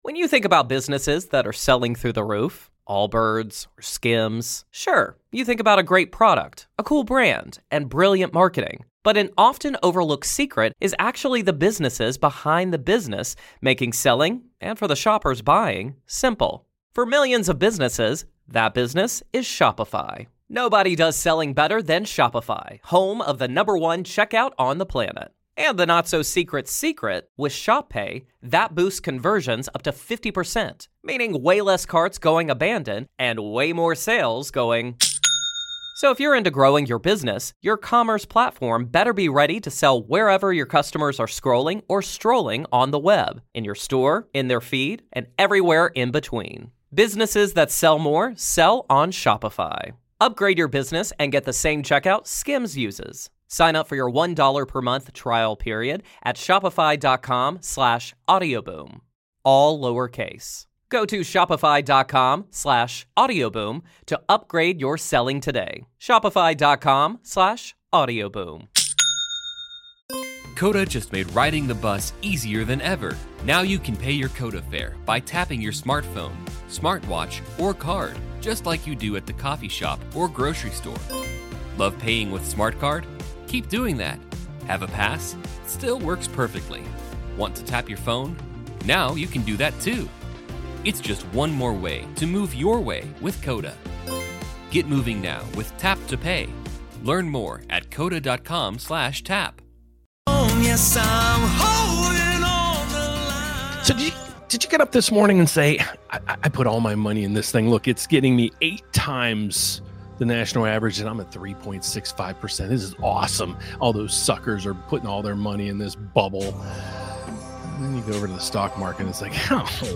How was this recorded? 📈 STOCK TALK TUESDAY (LIVE)